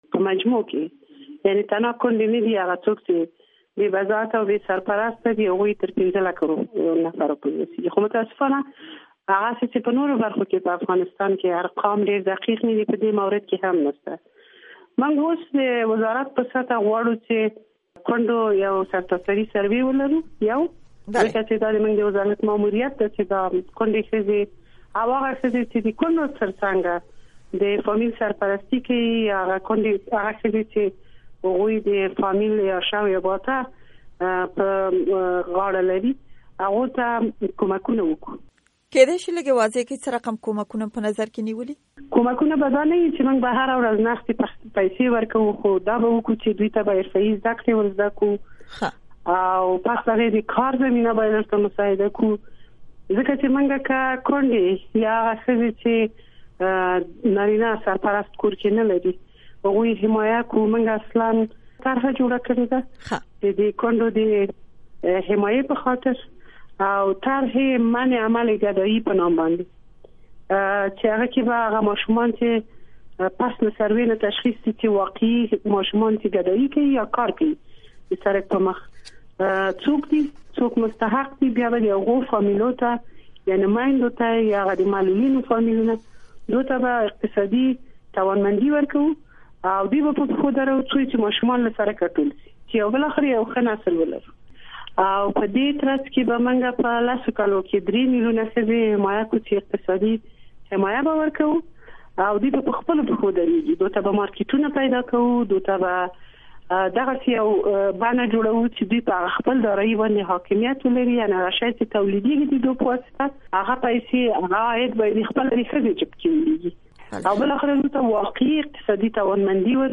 بشپړه مرکه